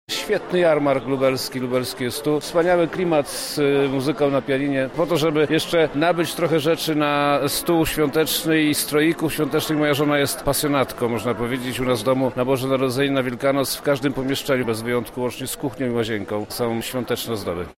Wojewoda lubelski, Przemysław Czarnek odwiedził jarmark, aby rodzinnie spędzić czas na wspólnych zakupach: